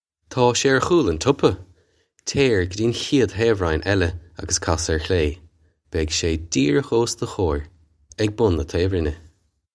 Pronunciation for how to say
Taw shay air khool on chuppa. Chair guh dyee on khayd tayv-rine ella uggus kass air khlay. Beg shay dyeer-ukh ose duh kho-ir, ig bun nah tayv-rinn-eh.
This is an approximate phonetic pronunciation of the phrase.